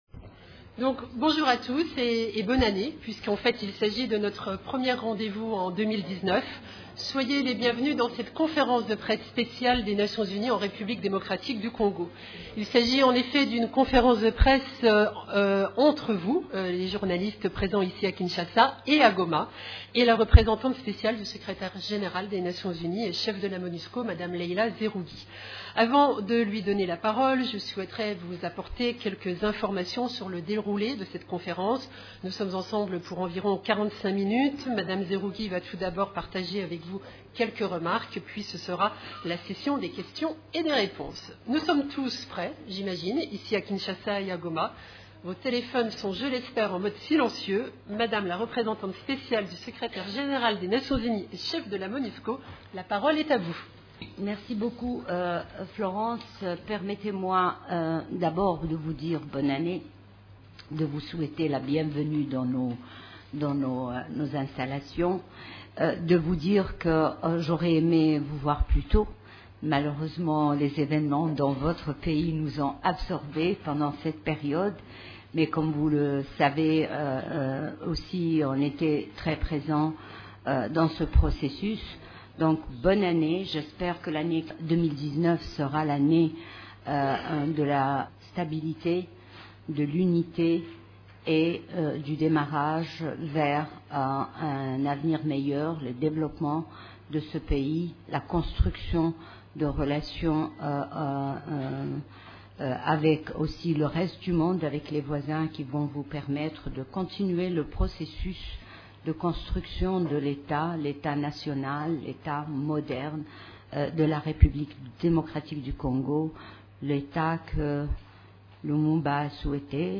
Lors de sa toute première conférence de presse de cette année, mercredi 30 janvier, la Représentante spéciale du Secrétaire général de l’ONU en RDC, Leila Zerrougui, a salué la maturité extraordinaire du peuple congolais.